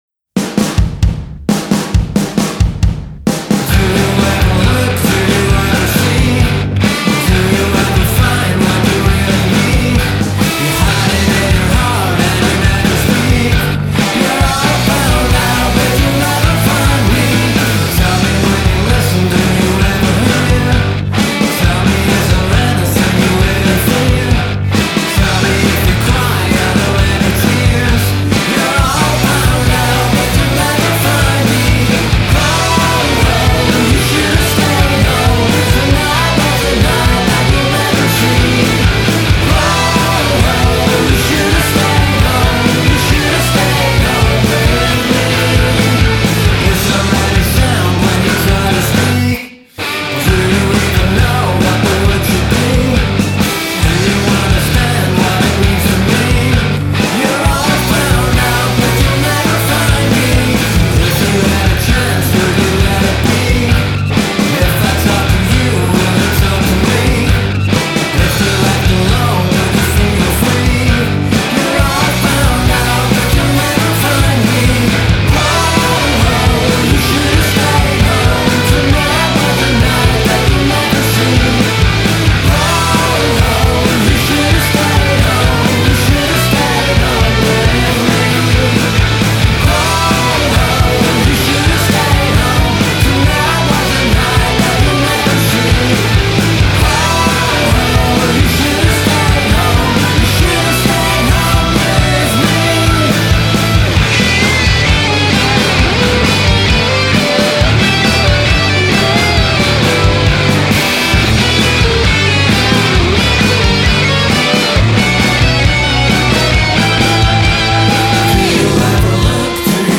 a raucous track of wall-to-wall indie-rock guitars and drums